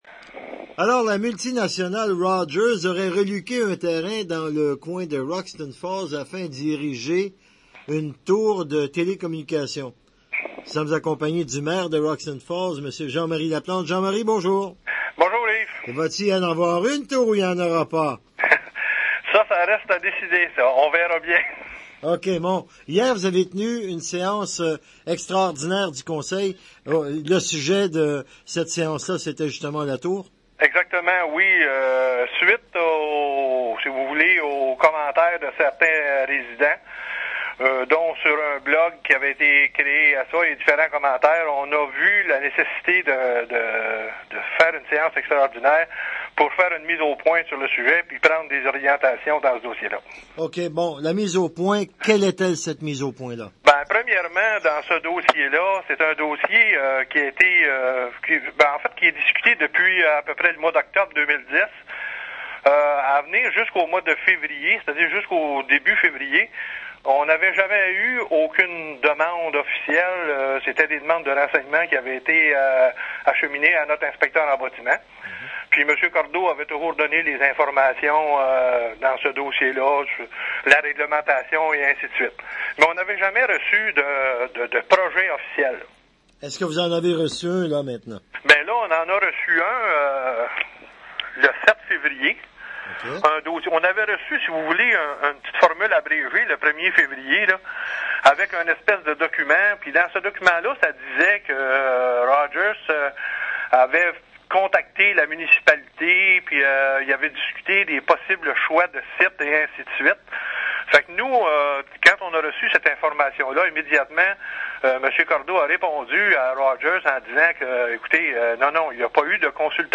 entrevue maire roxton falls.mp3